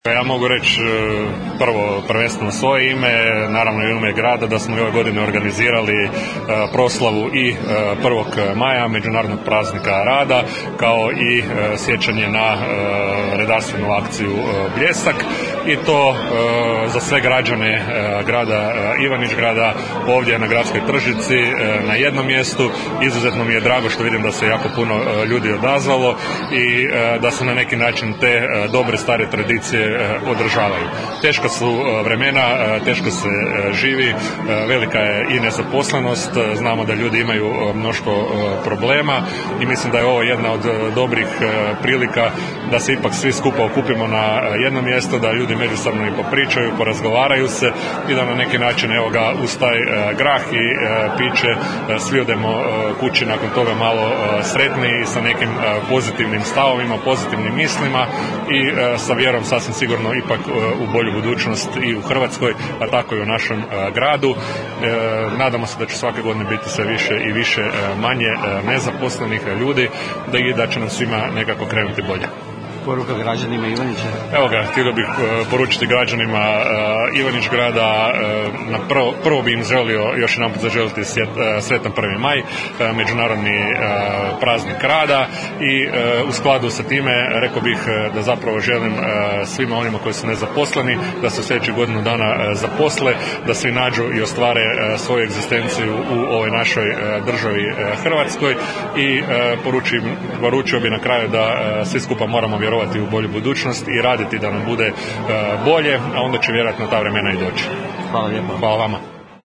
IZJAVA GRADONAČELNIKA JAVORA BOJANA LEŠA